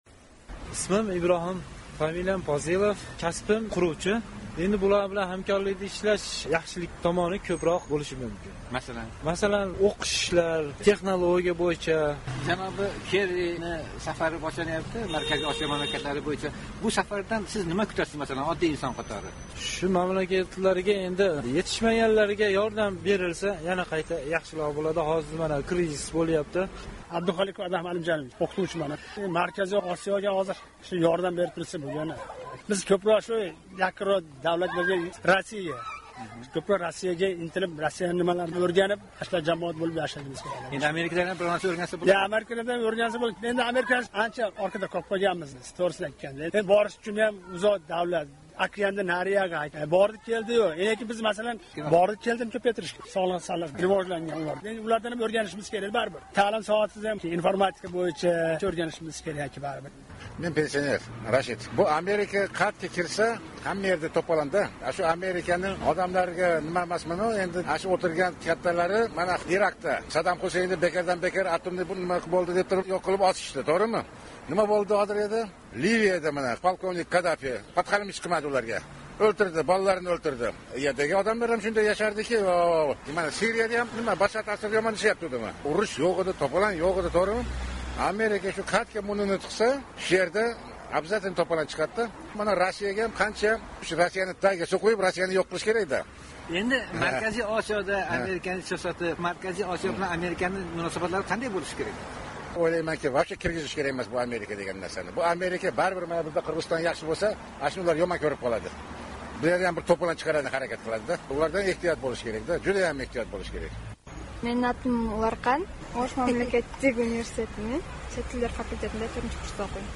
Kerrining Markaziy Osiyo safari haqida muloqot